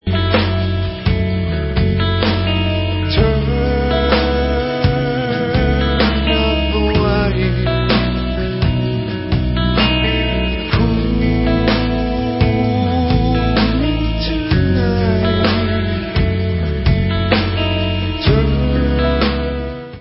Allstar dutch rockband